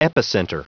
Prononciation du mot epicenter en anglais (fichier audio)
Prononciation du mot : epicenter